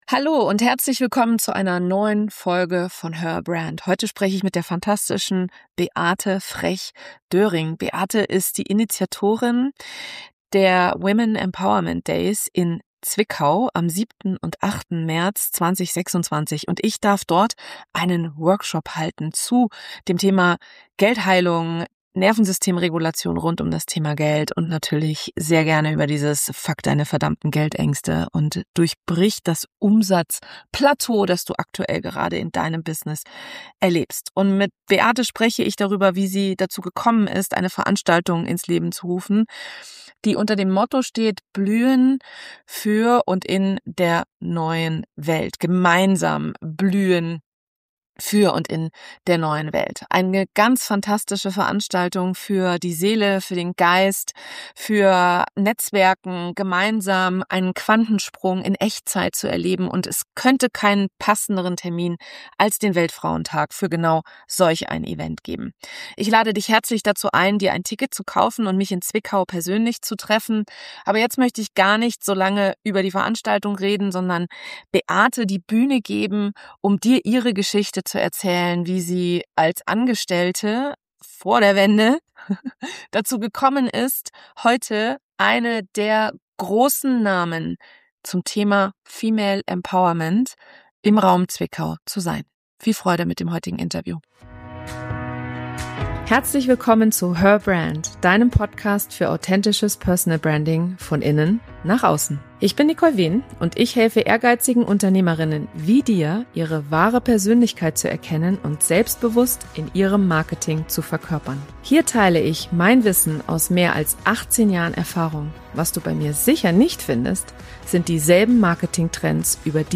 #305 Gemeinsam blühen – für und in der neuen Welt | Im Interview